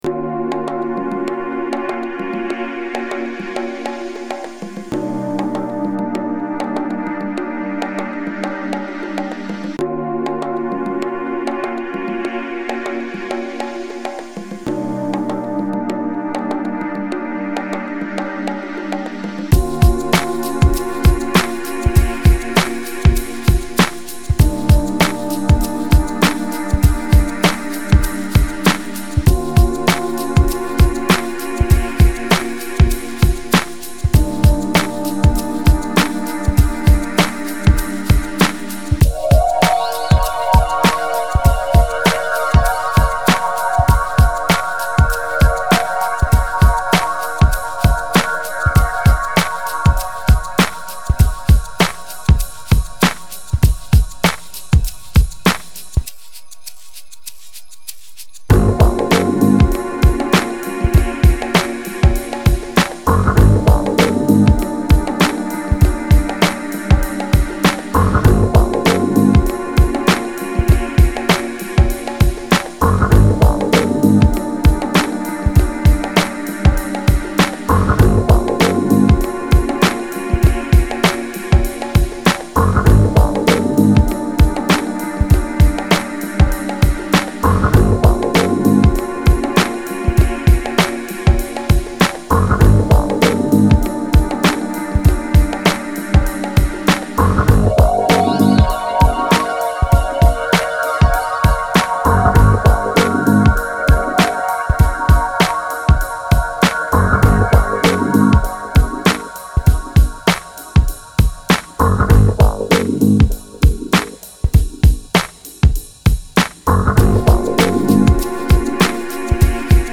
Chill